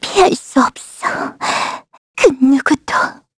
Xerah-Vox_Dead_kr.wav